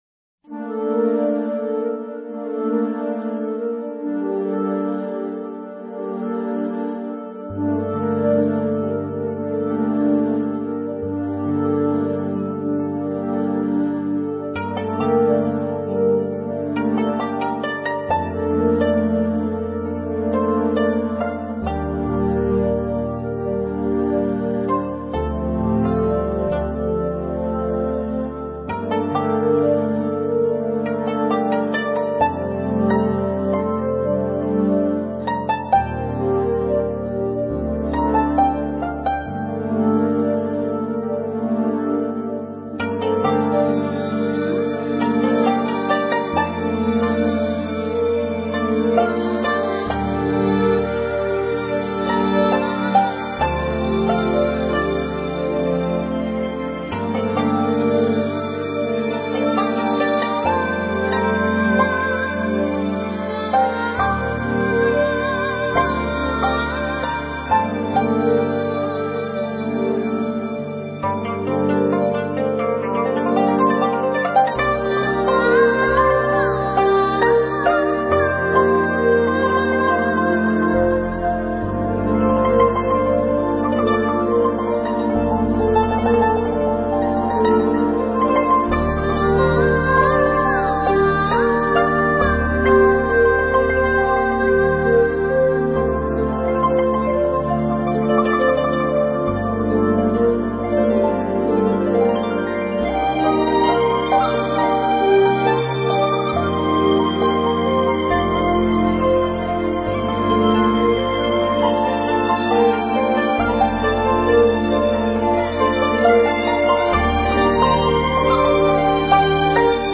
仓央嘉措情诗：《见或不见》（长相守/古筝）